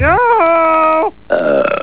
Wanna hear Barney's Burp?  well, just click on Bart
woooburp.wav